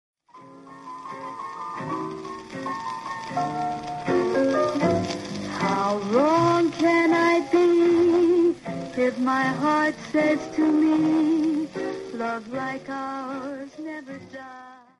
Unveröffentlichte Studioaufnahme
Los Angeles, 1948